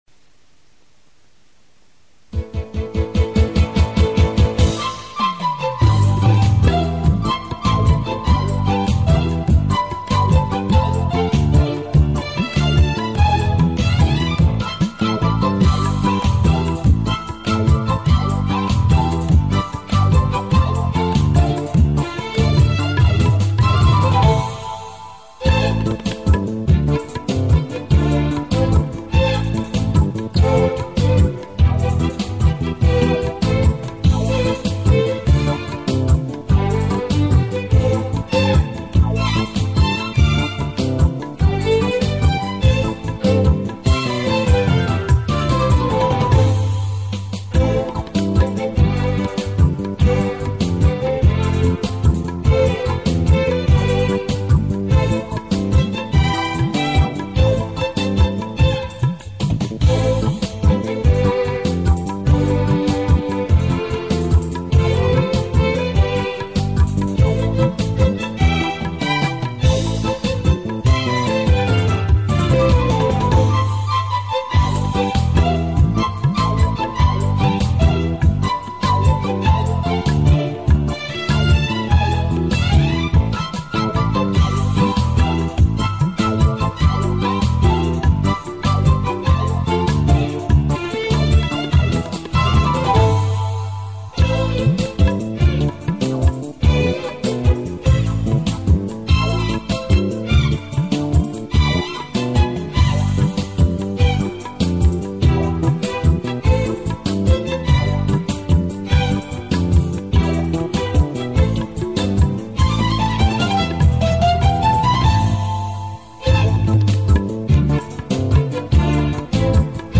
بیکلام